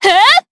DarkFrey-Vox_Attack4_jp_b.wav